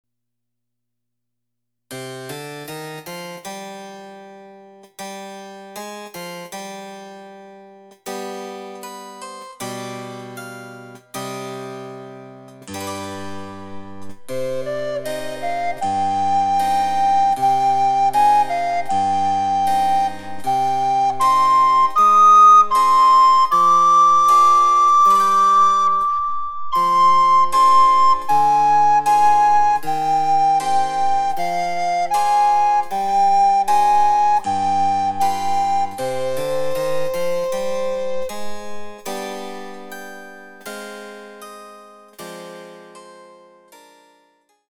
・リコーダー演奏例
デジタルサンプリング音源使用